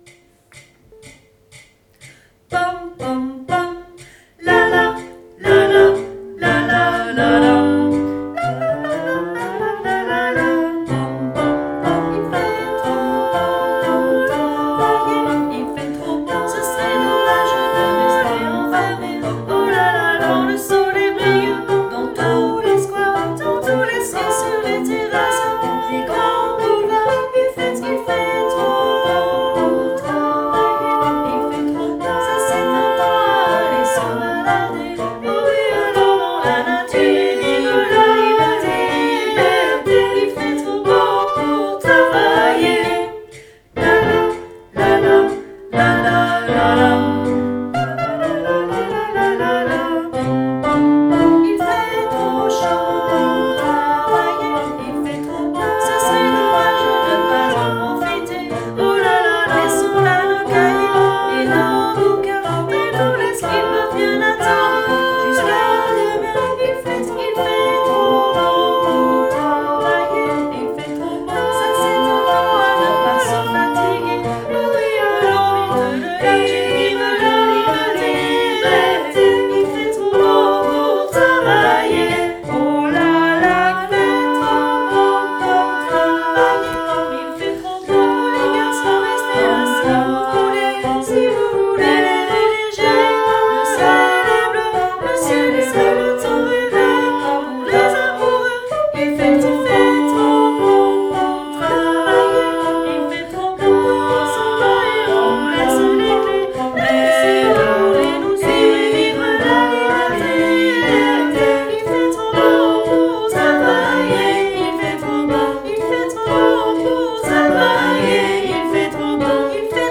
Enregistrements de travail
Il-fait-trop-beau-tutti.mp3